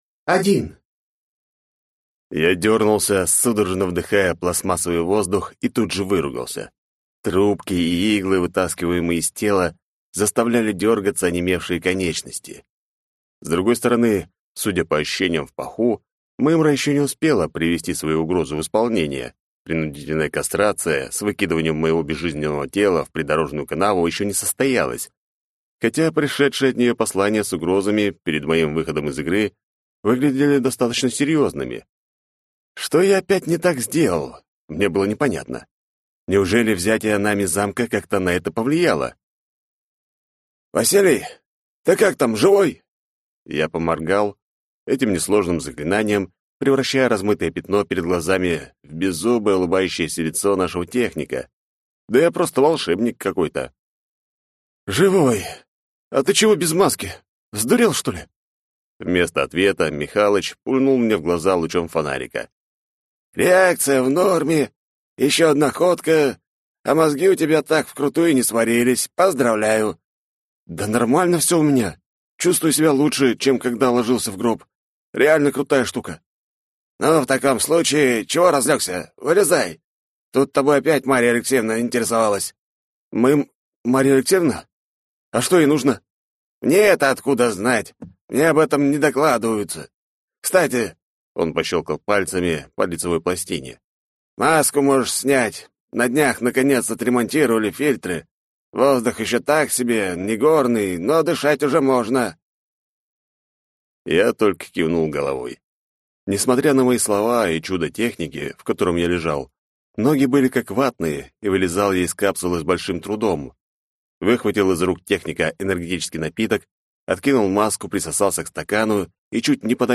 Аудиокнига Мир жизни и смерти. Книга 4 | Библиотека аудиокниг